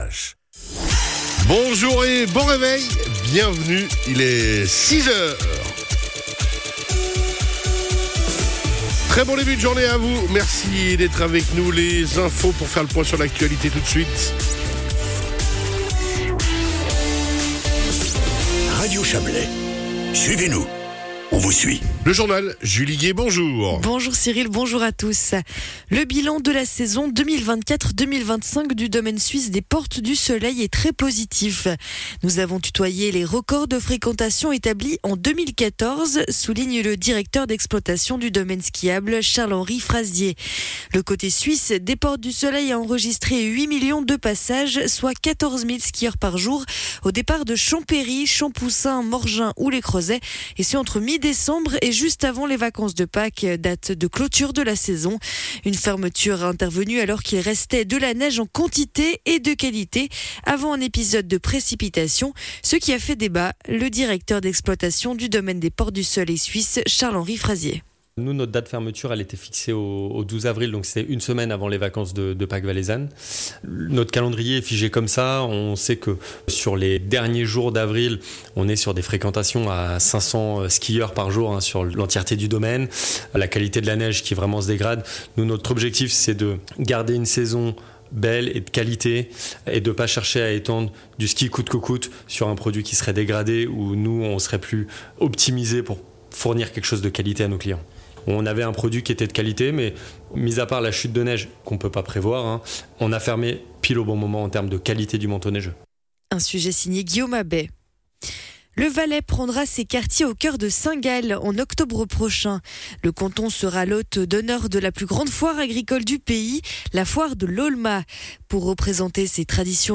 Le journal de 6h00 du 29.04.2025